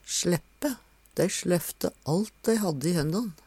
sLeppe - Numedalsmål (en-US)